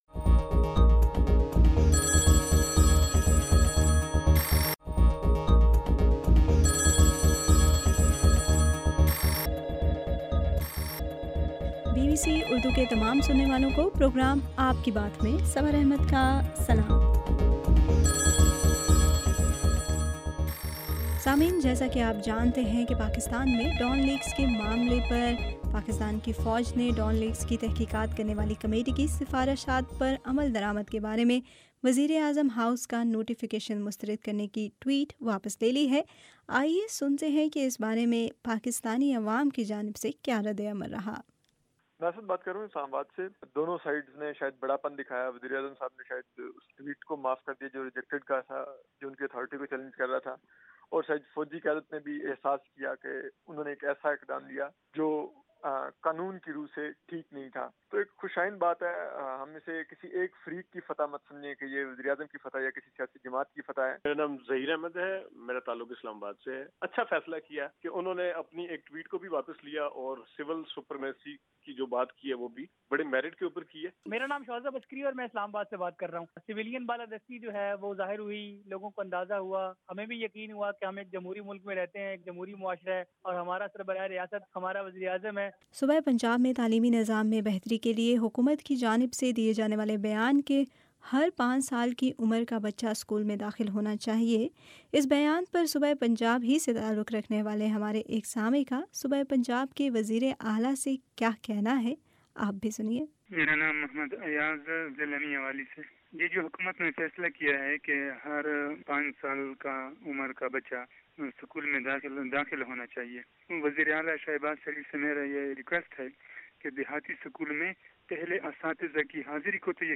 آپ کے صوتی پیغامات پر ، مبنی پروگرام ” آپ کی بات ٌ